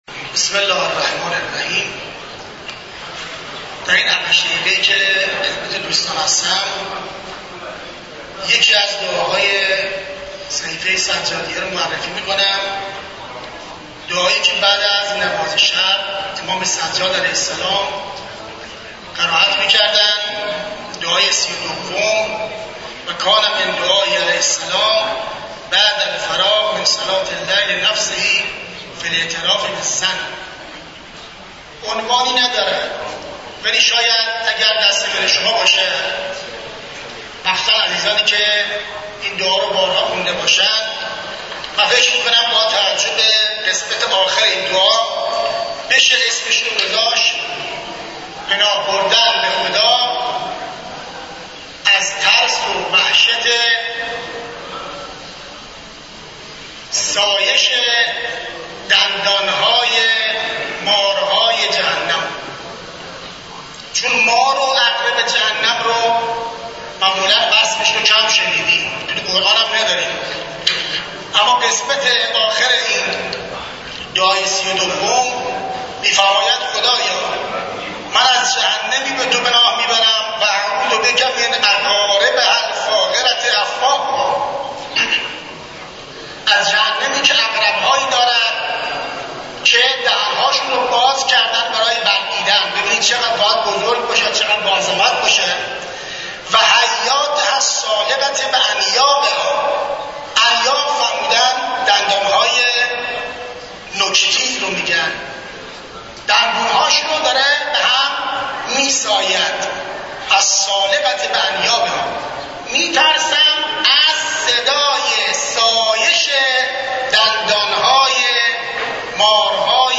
سخنرانی
در شرح دعای ۳۲ صحیفه سجادیه در مسجد دانشگاه کاشان